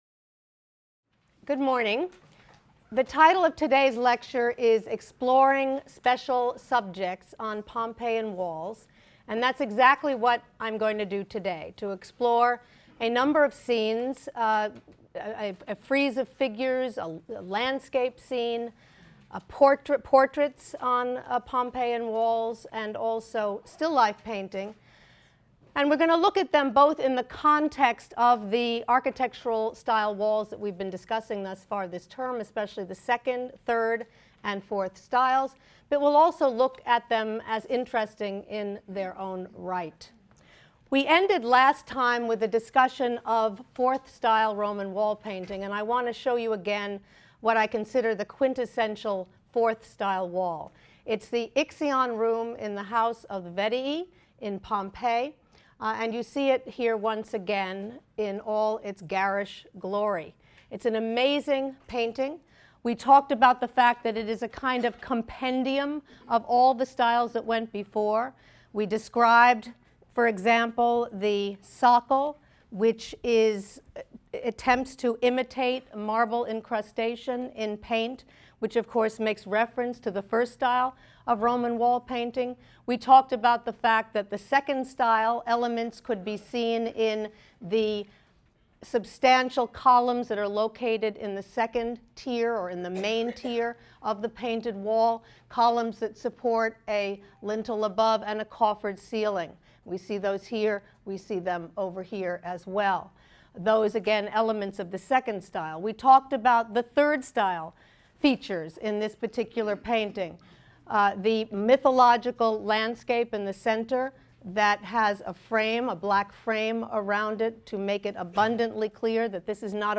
HSAR 252 - Lecture 8 - Exploring Special Subjects on Pompeian Walls | Open Yale Courses